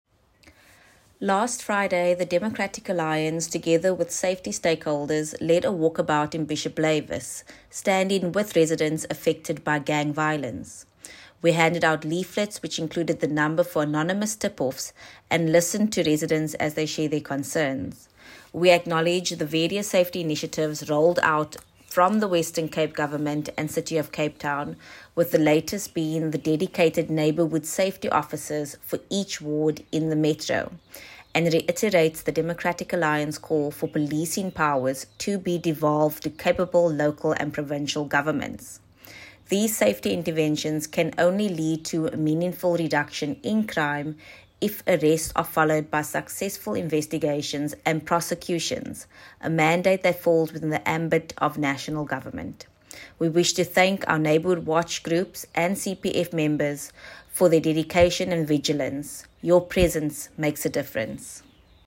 soundbite by Alexandra Abrahams